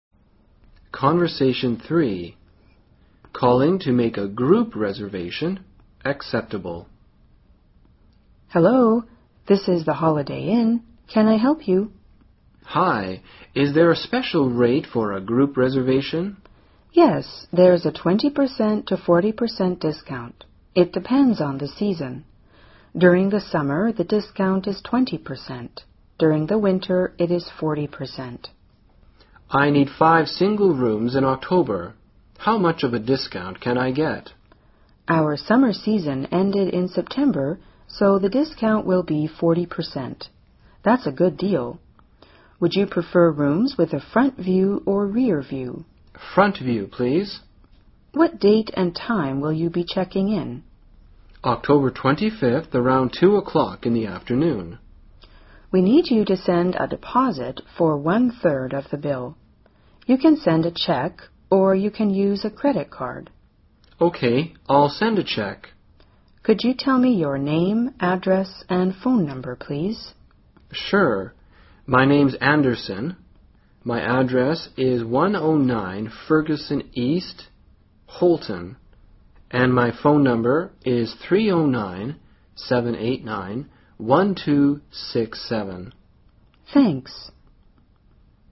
【对话3：打电话预约房间（预定成功）】